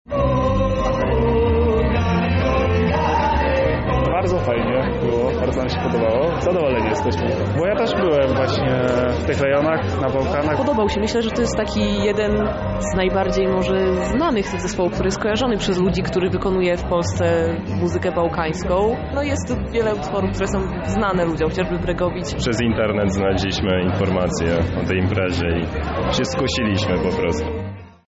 O tym jak bawili się podczas występu mówią sami widzowie.